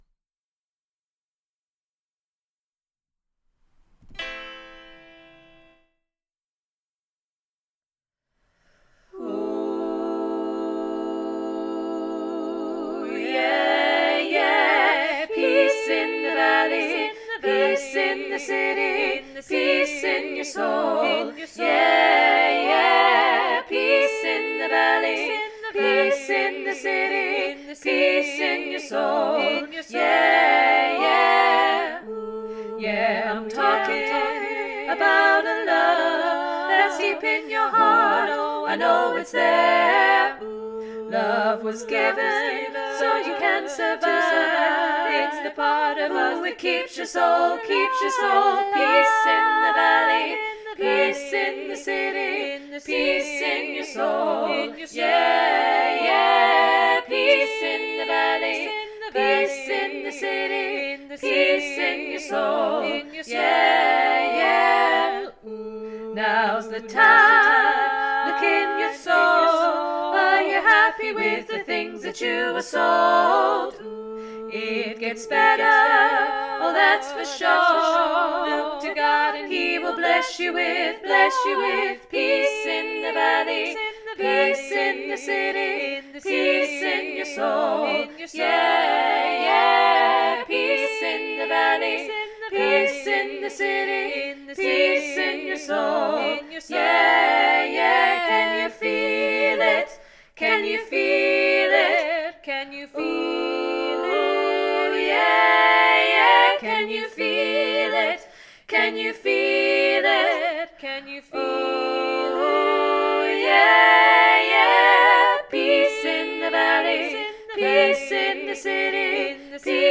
Peace 3VG Revamp SATB